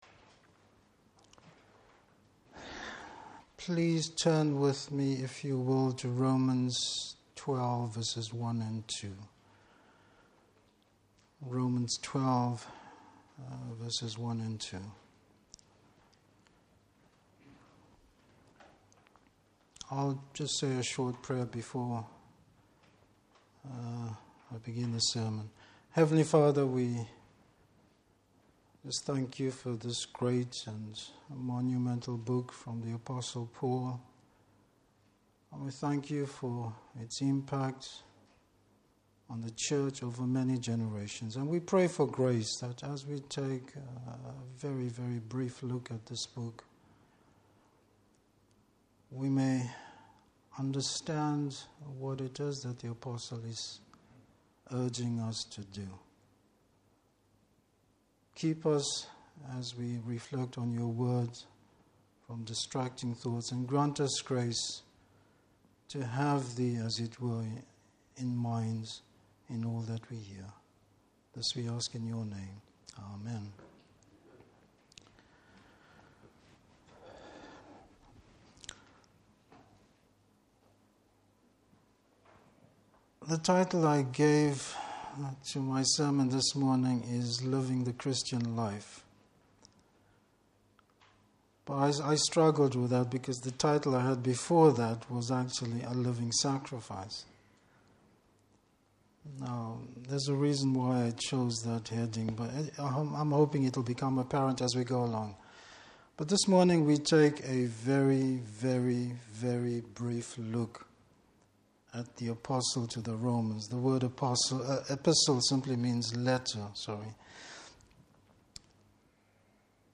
Service Type: Morning Service Bible Text: Romans 12:1-2.